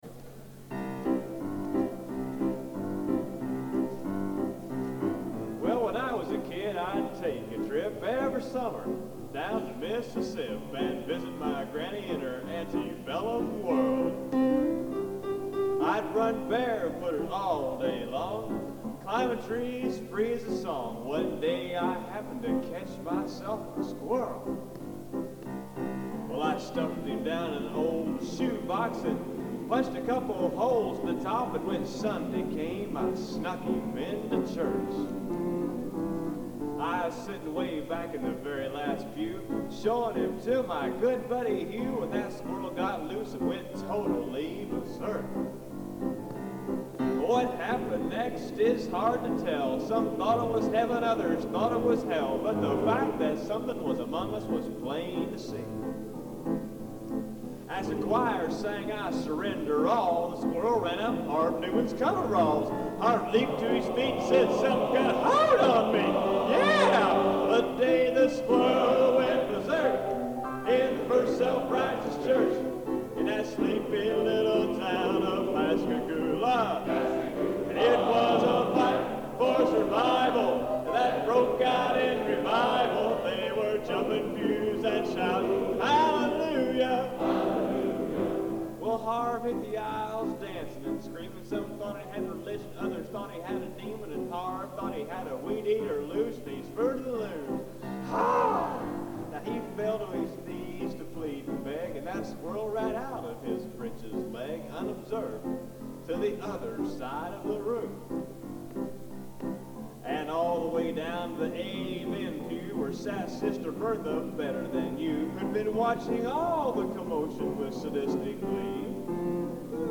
Location: Old Academy of Music, Stockholm, Sweden